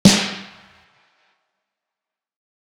Crack Sizzle.wav